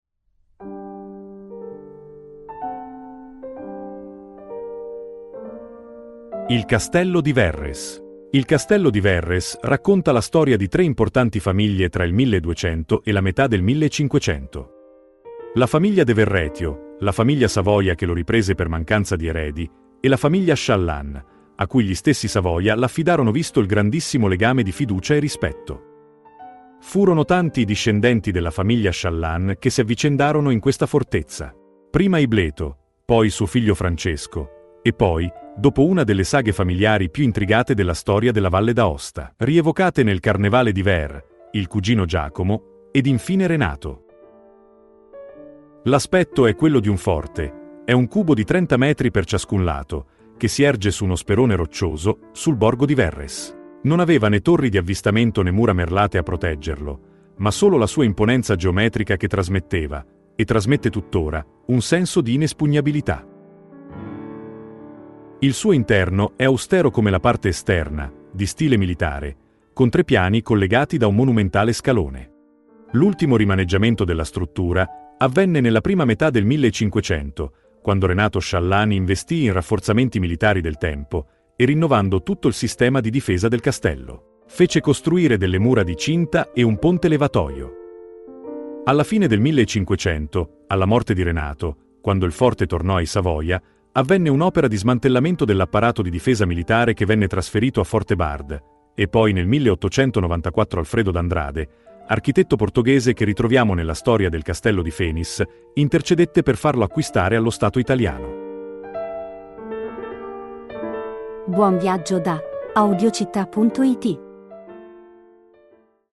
Audioguida – Castello di Verres